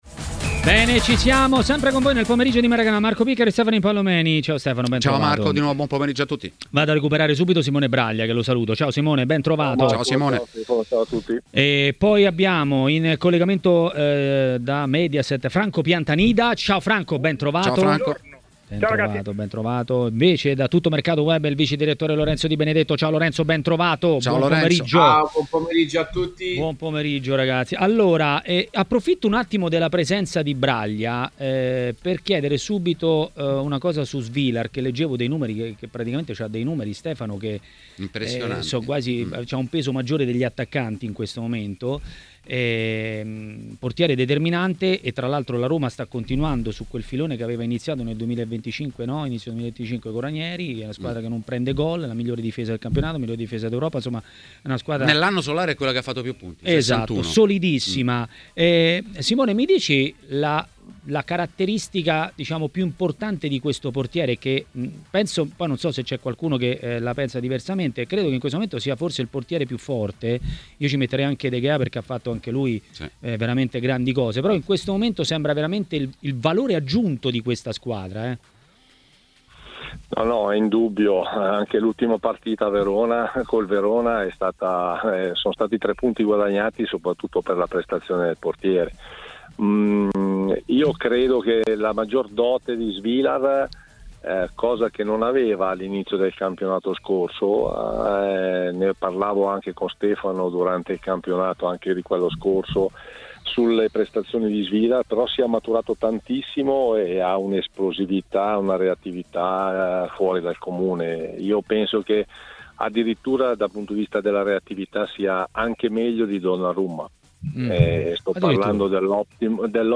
A Maracanà, nel pomeriggio di TMW Radio, è intervenuto il giornalista ed ex calciatore Stefano Impallomeni.